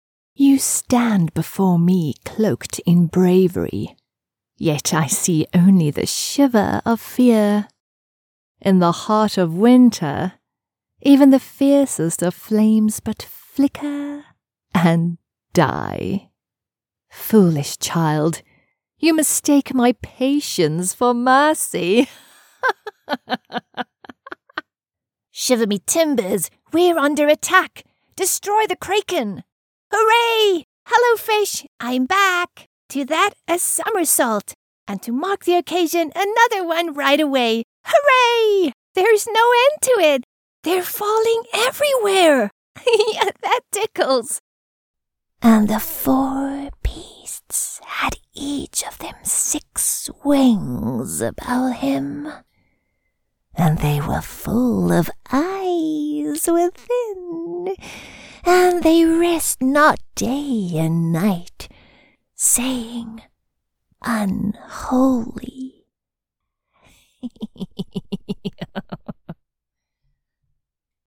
English (South African)
Video Games
Behringer C1 Condenser microphone
Sound-proofed room
HighMezzo-Soprano